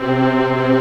Index of /90_sSampleCDs/Giga Samples Collection/Organ/WurlMorton Brass